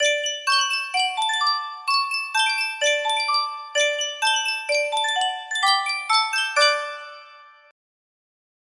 Sankyo Miniature Music Box - WJFOBC JAX music box melody
Full range 60